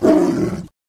tiger
attack2.ogg